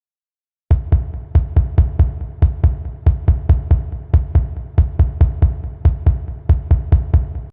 The horror mode oren is a high-energy audio clip commonly used in memes, TikToks, and YouTube shorts Game SFX sound and meme culture.
Featuring a punchy beat with iconic "horror", "mode", "oren", it's a staple in modern Game SFX sound and meme culture.